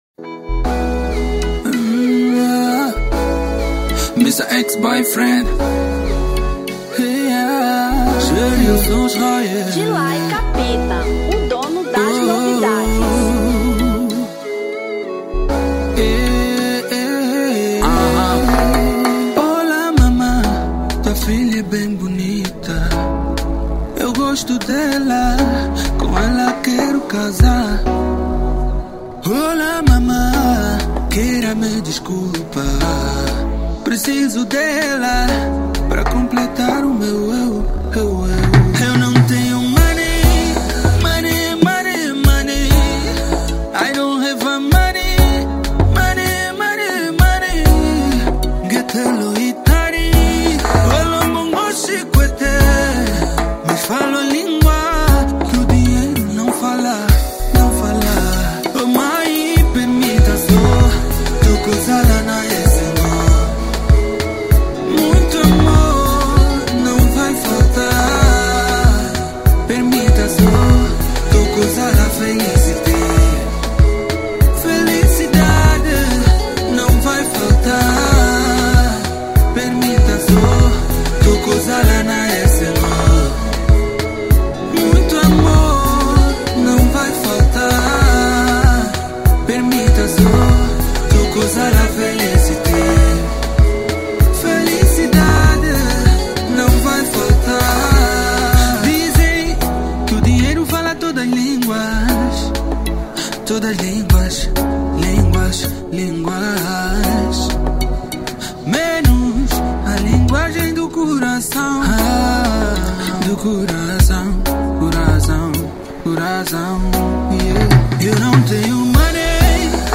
Kizomba 2019